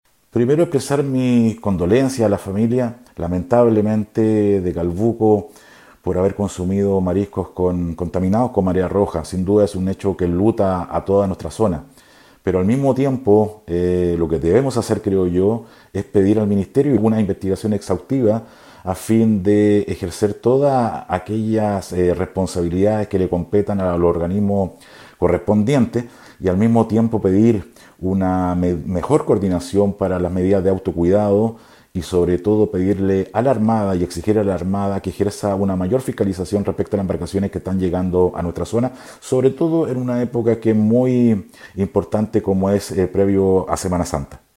Similar postura adoptó el diputado Héctor Ulloa, expresando que se pedirá al ministerio de Salud las explicaciones del caso, y mayor fiscalización a la Armada.